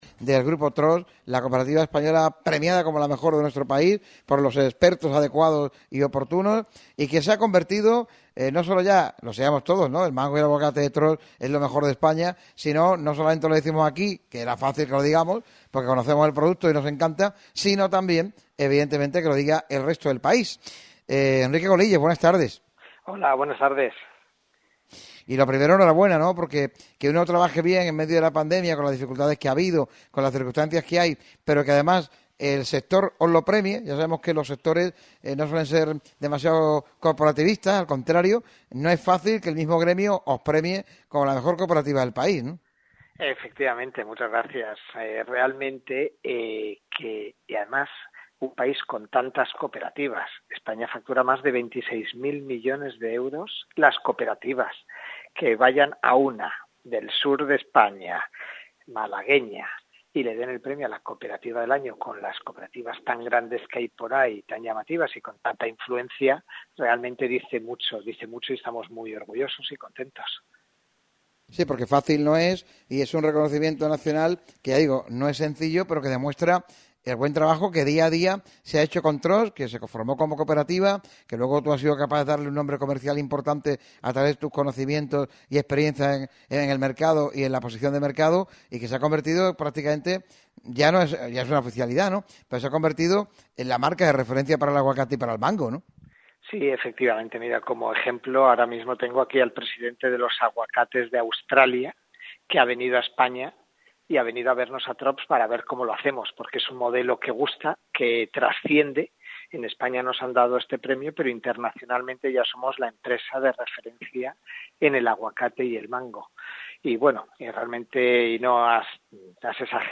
pasó por el micrófono rojo de Radio MARCA Málaga para analizar la actualidad de la empresa y comentar ese premio a la mejor cooperativa española recogido recientemente.